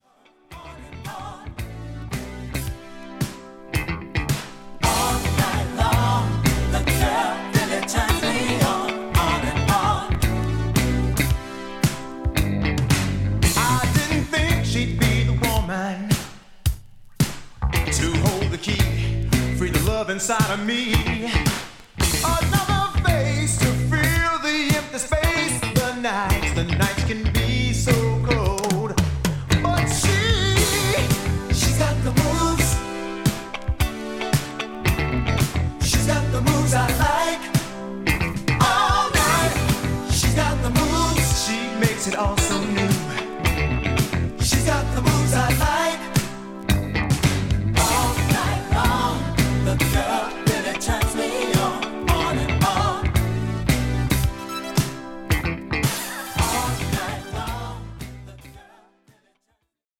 ホーム ｜ SOUL / FUNK / RARE GROOVE / DISCO > SOUL